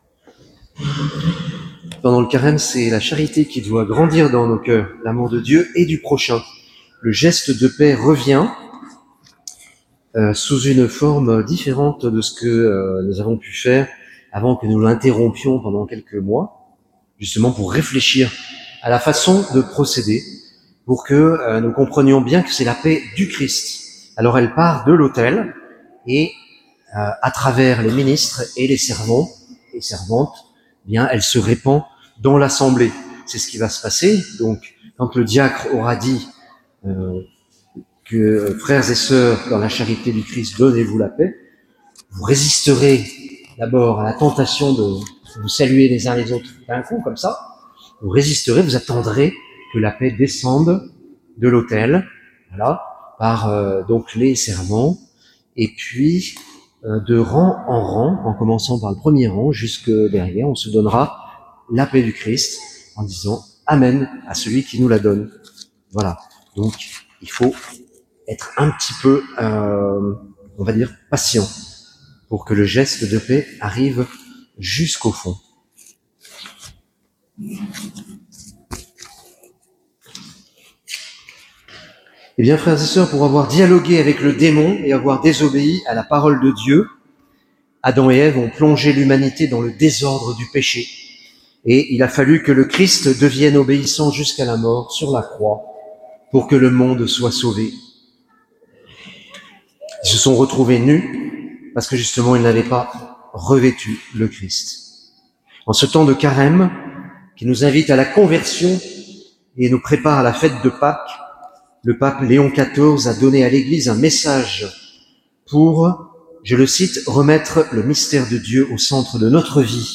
Homélie de la solennité de la Sainte Trinité (B)